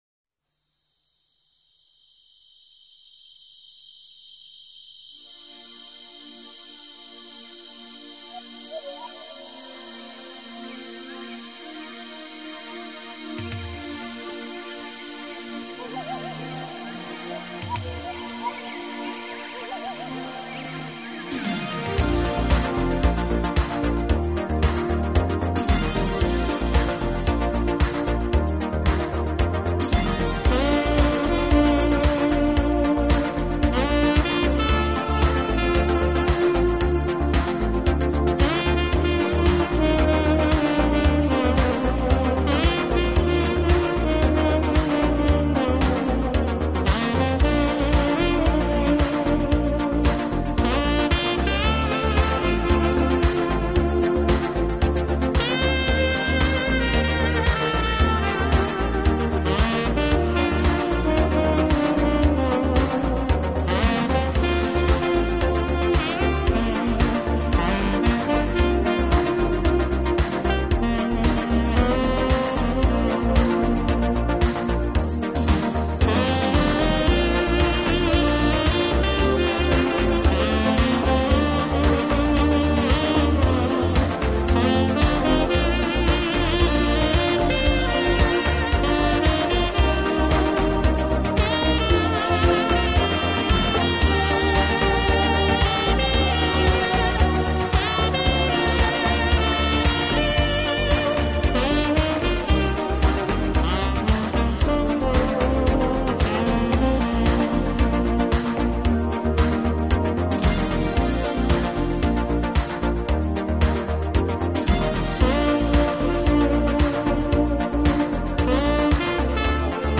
以萨克斯管为主，曲目
演奏轻快、柔和、优美，带有浓郁的爵士风味。